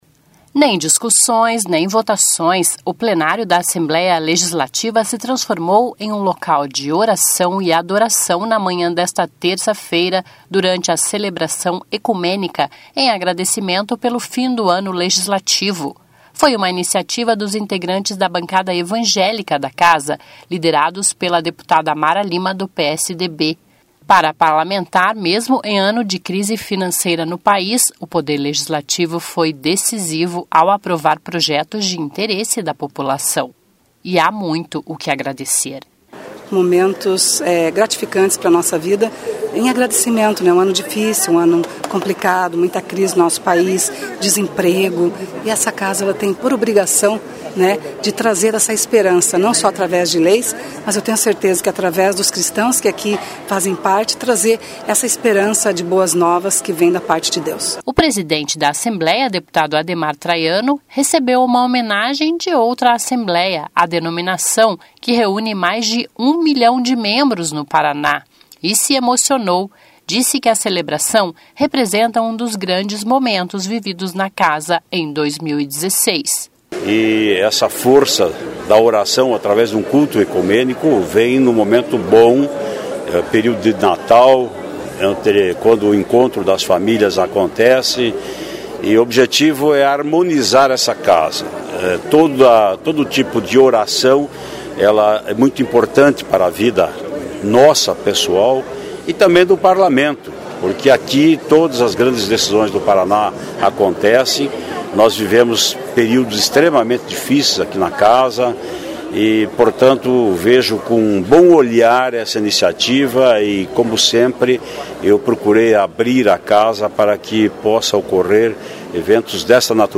O Plenário da Assembleia se transformou em um local de oração e adoração na manhã desta terça-feira (13) durante a celebração ecumênica em agradecimento pelo fim do ano legislativo.